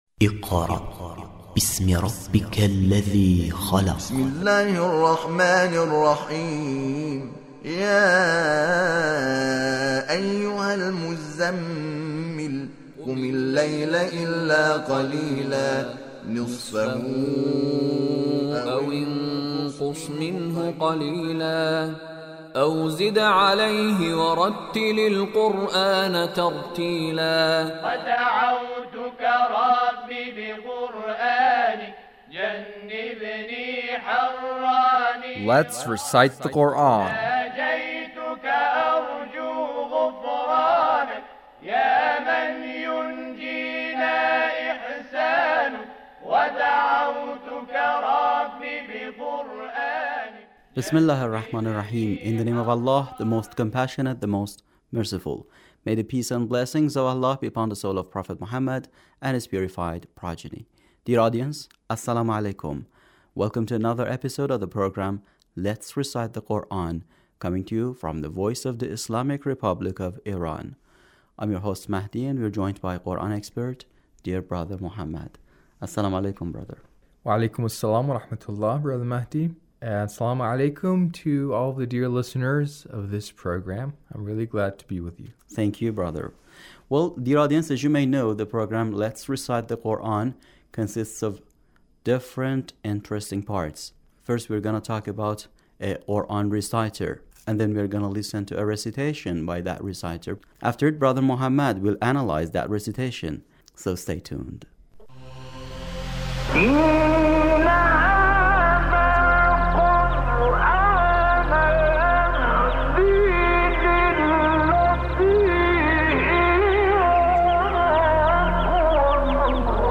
Recitation of brother Hamed Shakernejad